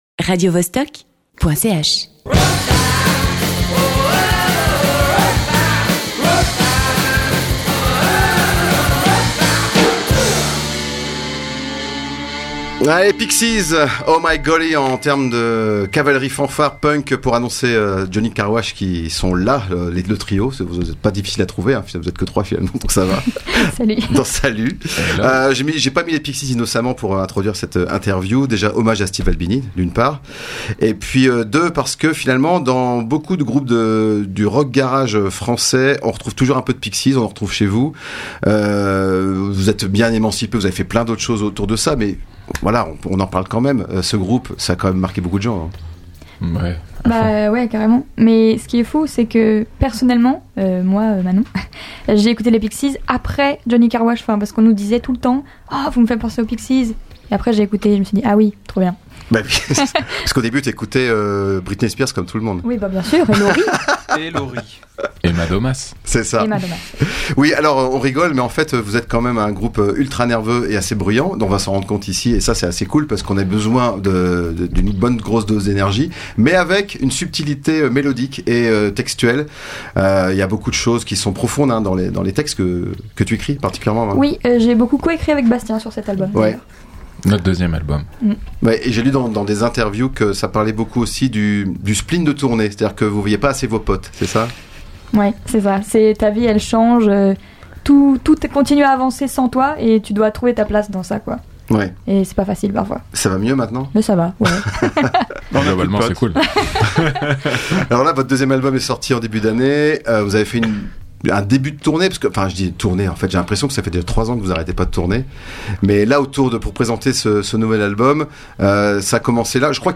Le trio lyonnais Johnnie Carwash était en interview avant leur concert du 25 mais 2024 dans nos studios.